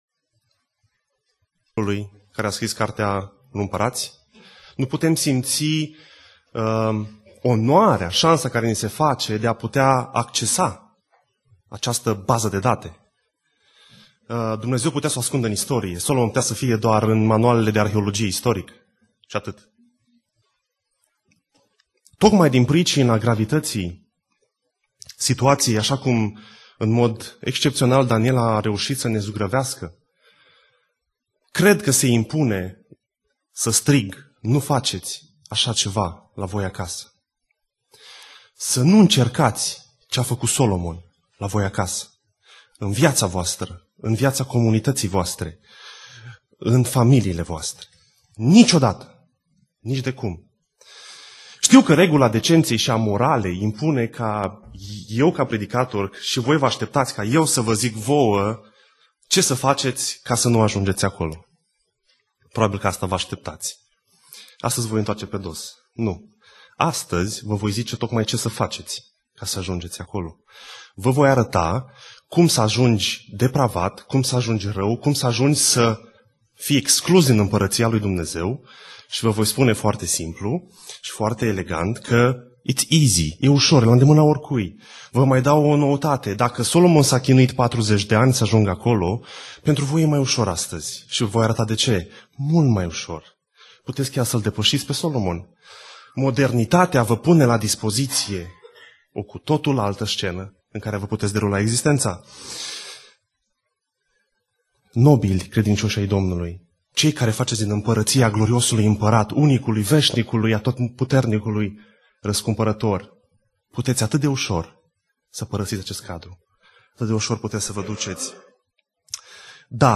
Predica Aplicatie - 1 Imparati Cap 9-11